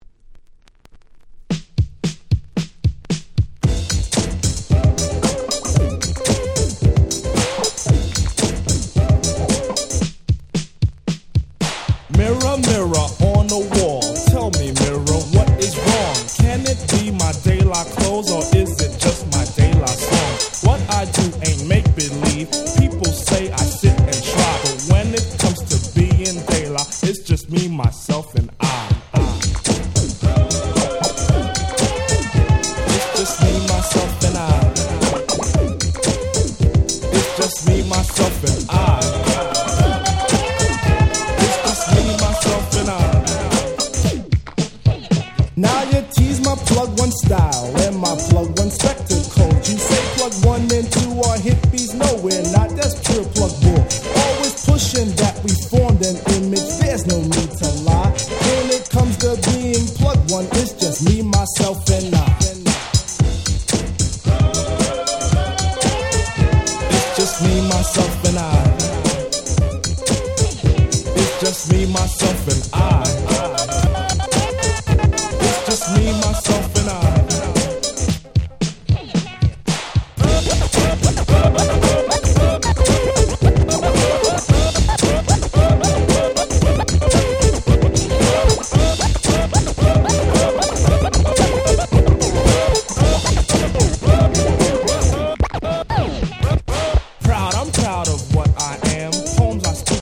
89' Super Hit Hip Hop !!